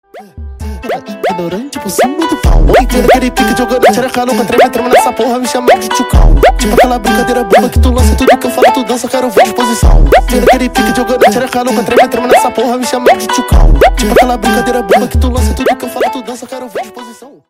HipHop / Rock